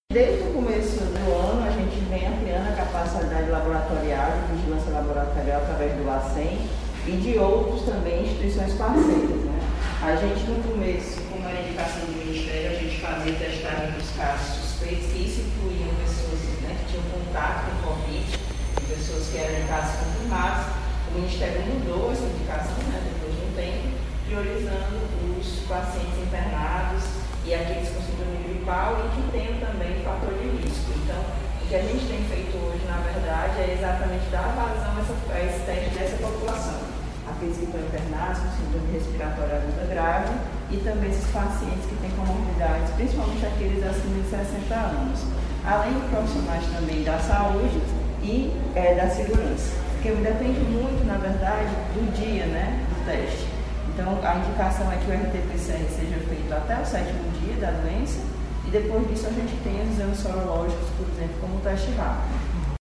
Magda Almeida fala sobre a ampliação da capacidade laboratorial e destaca a prioridade da rede de saúde.